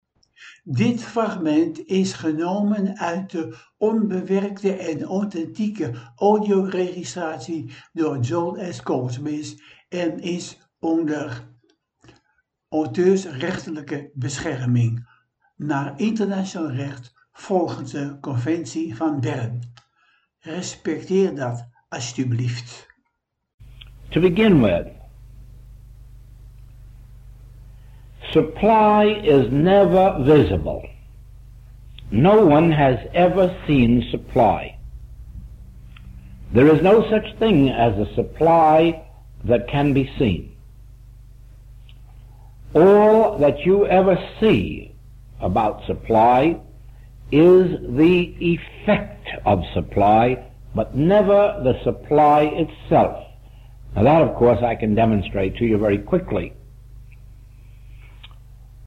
ENKELE FRAGMENTEN UIT DE AUTHENTIEKE, ONBEWERKTE AUDIOREGISTRATIES